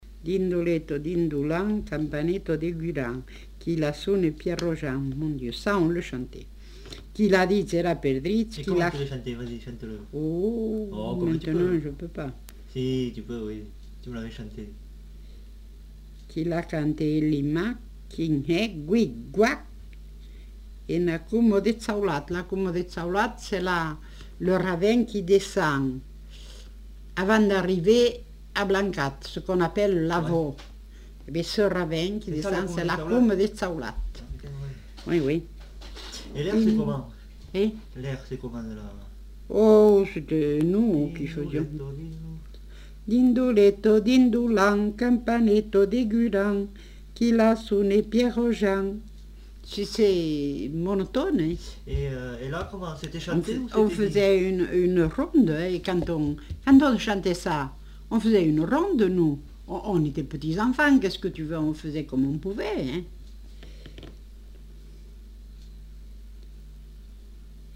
Formulette enfantine
Aire culturelle : Comminges
Lieu : Montauban-de-Luchon
Genre : forme brève
Effectif : 1
Type de voix : voix de femme
Production du son : récité ; chanté